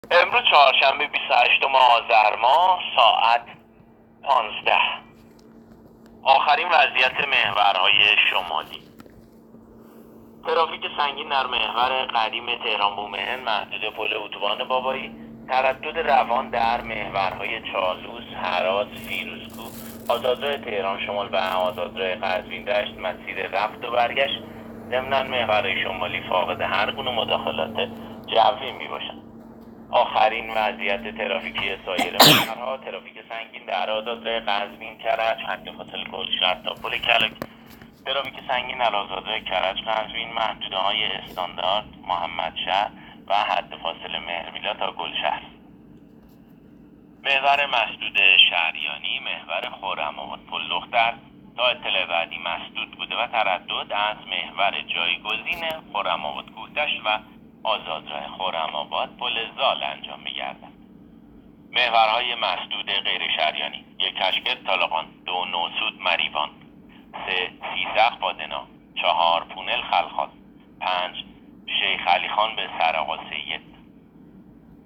گزارش رادیو اینترنتی از آخرین وضعیت ترافیکی جاده‌ها تا ساعت ۱۵ بیست و هشتم آذر؛